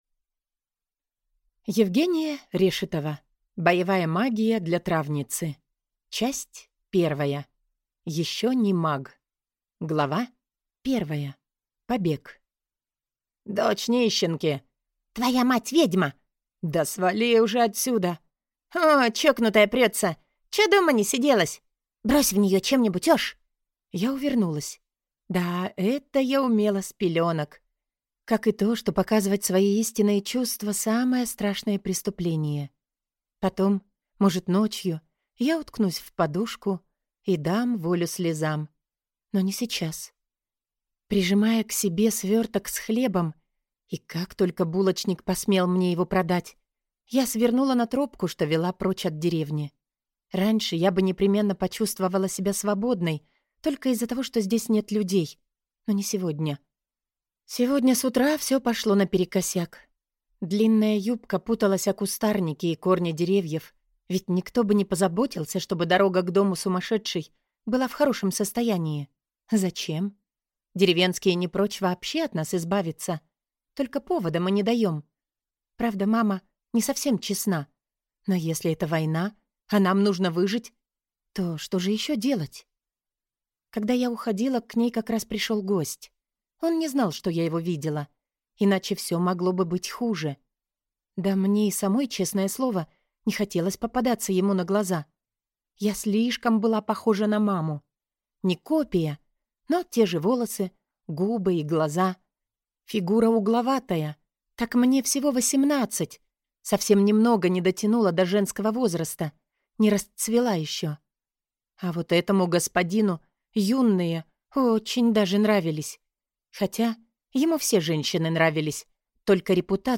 Аудиокнига Боевая магия для травницы | Библиотека аудиокниг